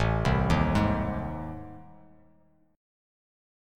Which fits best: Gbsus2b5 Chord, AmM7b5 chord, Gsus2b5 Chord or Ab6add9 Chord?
AmM7b5 chord